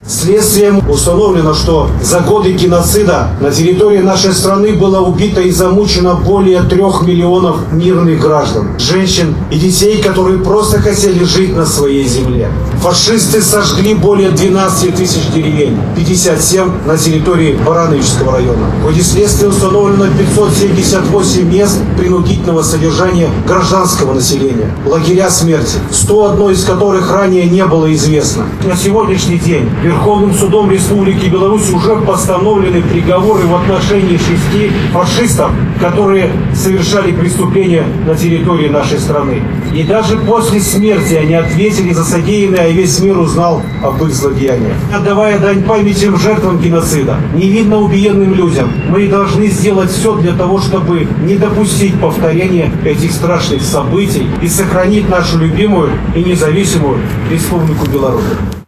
В преддверии значимой даты на станции «Барановичи-Центральные» состоялся митинг.